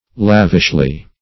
Lavishly \Lav"ish*ly\, adv.